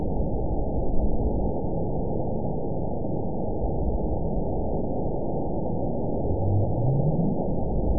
event 920485 date 03/27/24 time 23:08:41 GMT (1 year, 1 month ago) score 9.64 location TSS-AB02 detected by nrw target species NRW annotations +NRW Spectrogram: Frequency (kHz) vs. Time (s) audio not available .wav